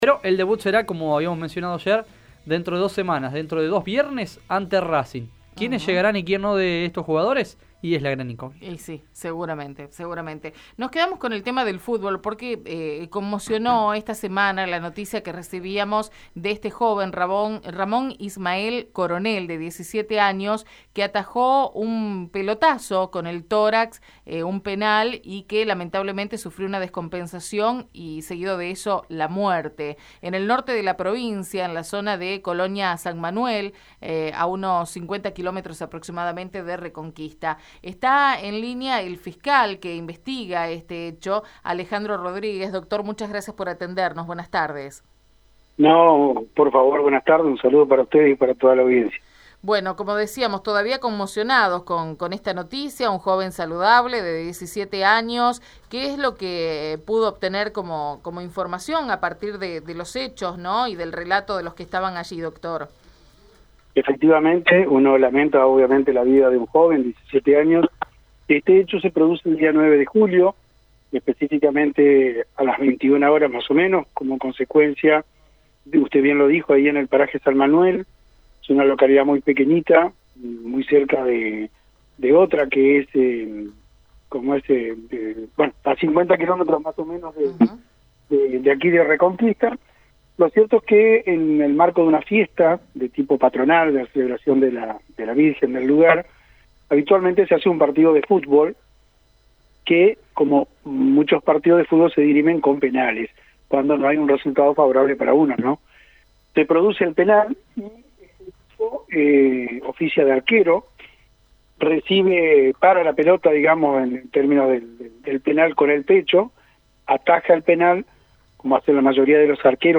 El fiscal interviniente, Alejandro Rodríguez indicó a través de Radio EME que  «no hay indicio de delito ya que nadie quiso matar a otro de un pelotazo».